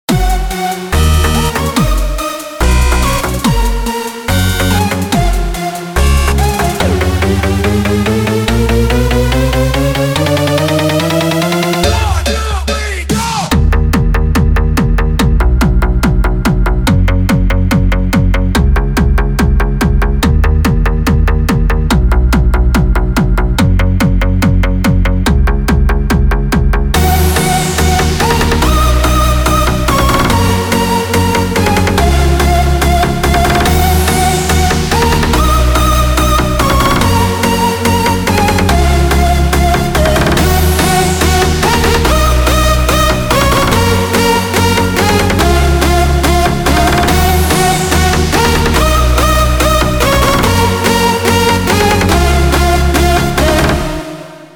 קצת חסר בהתחלה אבל זה בגלל שזה לpa700 ולא היה לי מספיק ערוצים אבל אשמח לתגובות